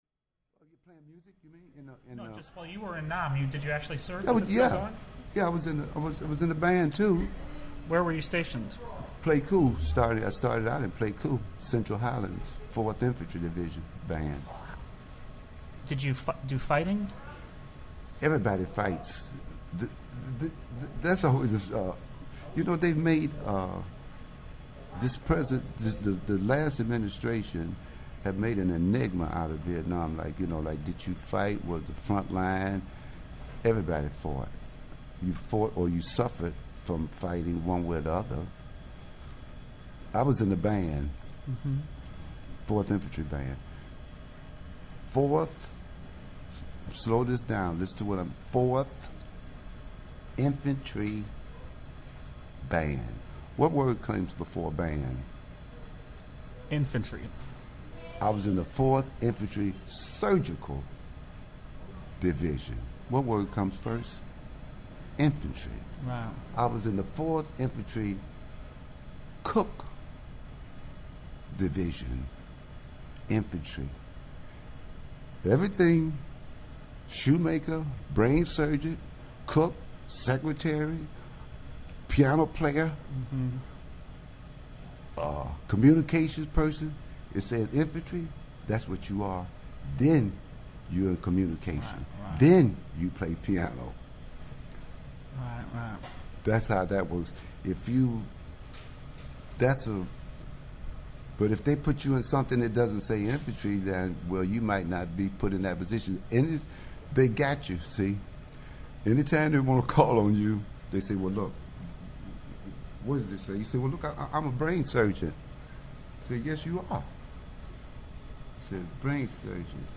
Interview with Henry Threadgill (Part 2)
Part two is about Vietnam, and features audio: You’ve got to hear him tell these war stories himself.